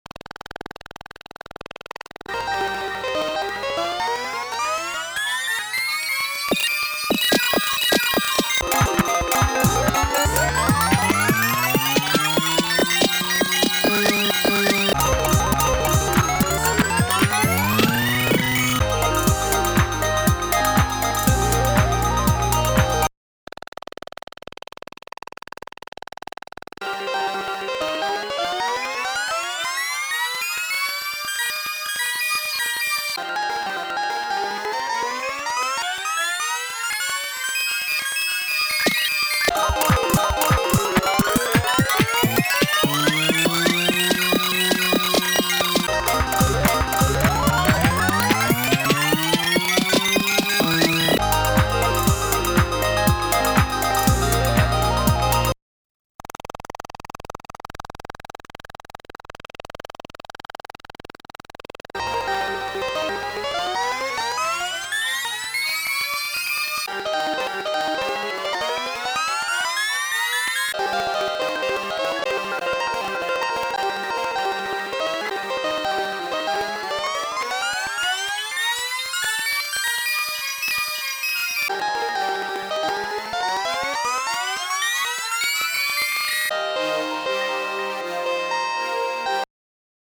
And… it seems they introduced a hidden glitch/tape effect in OB or a shit “detectroyer”.